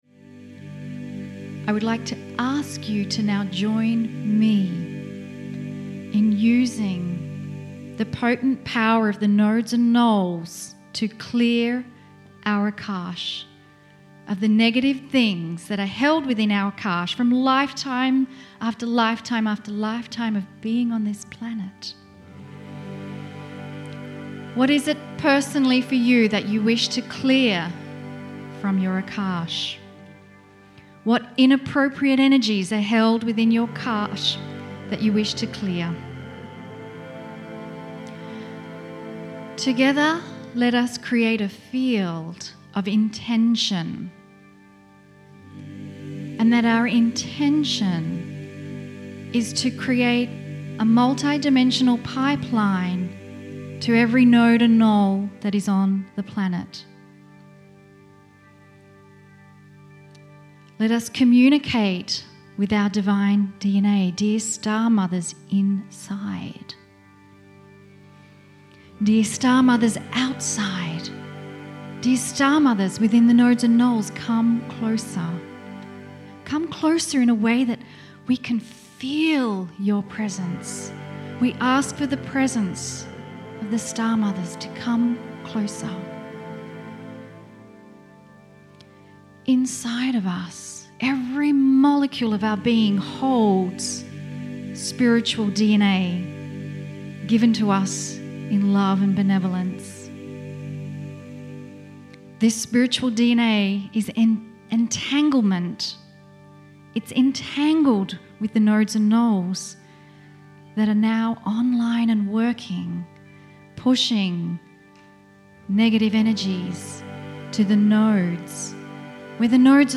MULTI - CHANNELLING